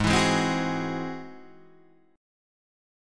Ab+7 chord